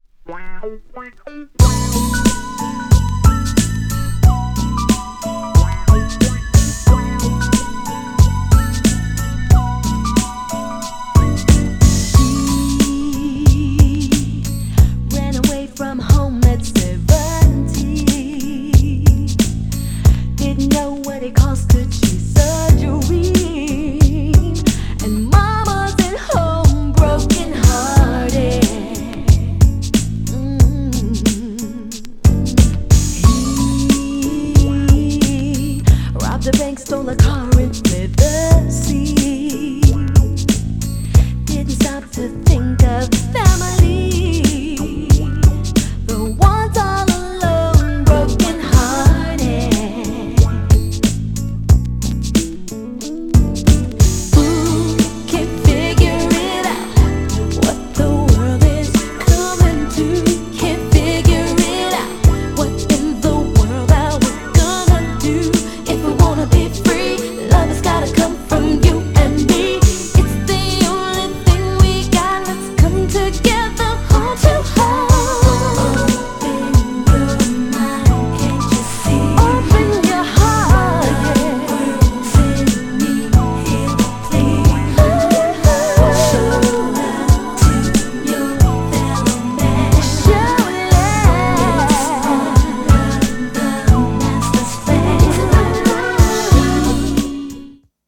GENRE R&B
BPM 86〜90BPM
90s_HIP_HOP_SOUL
哀愁系 # 女性コーラスR&B # 歌唱力最高